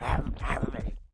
walk_act_1.wav